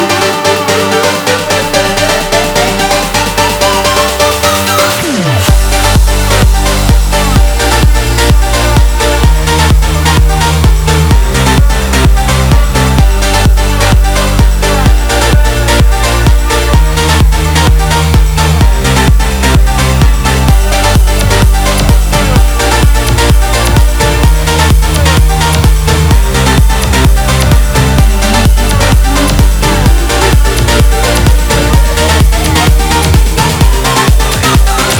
Жанр: Танцевальные / Хаус
House, Dance